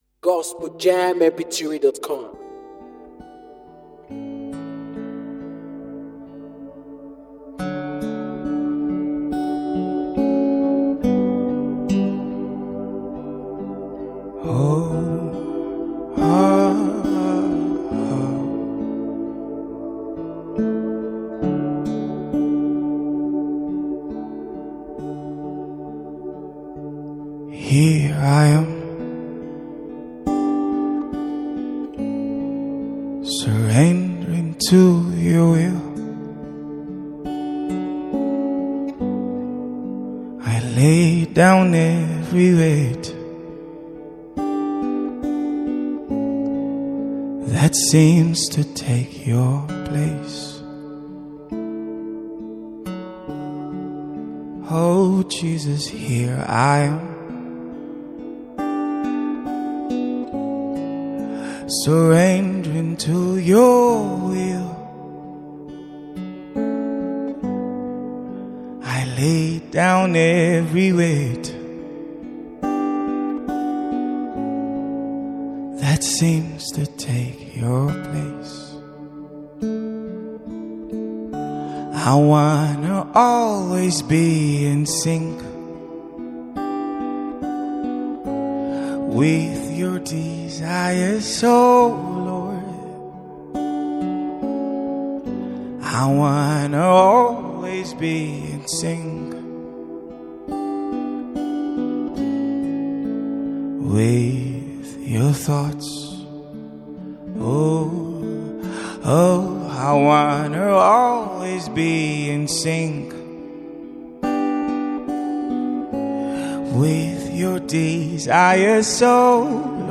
Acoustic guitar popular knowed gospel singer
a spoken words artist